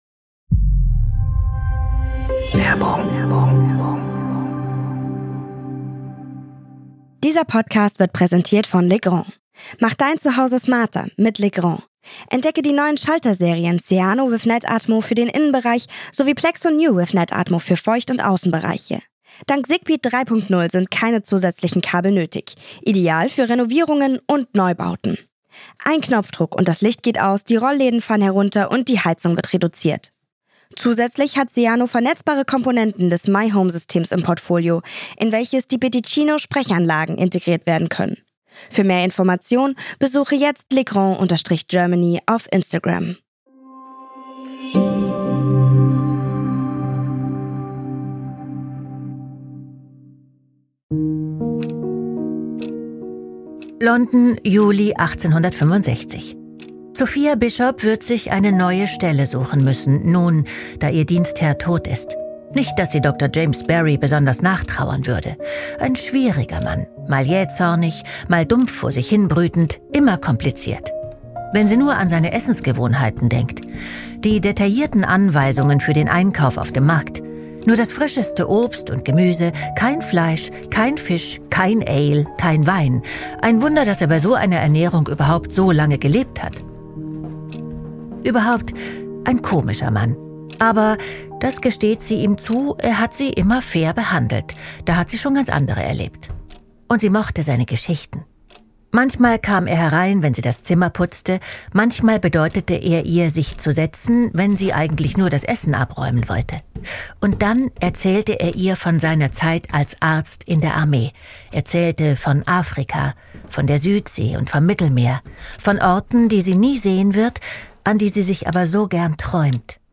Von Pistolenduellen, Geburtshilfe und einem großen Geheimnis: Andrea Sawatzki erzählt James Barrys Geschichte.